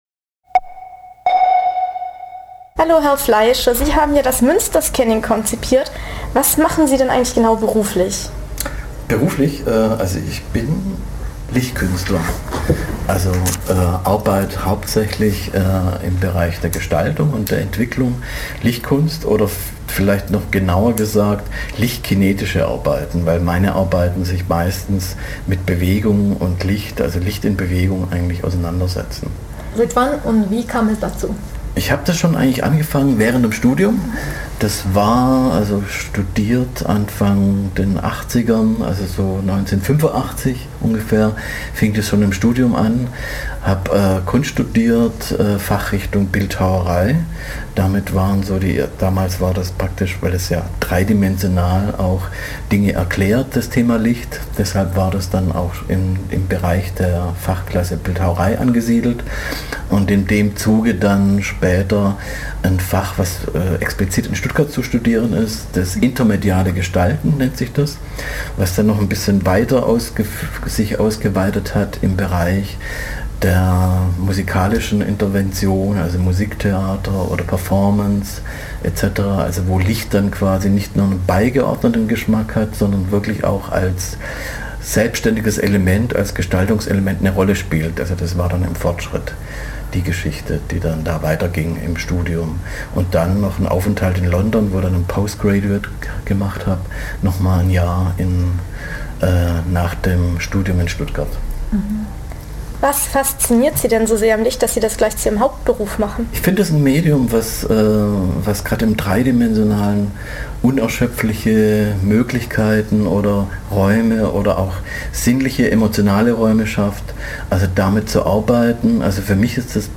Genre Radio
interview_muensterscanning.mp3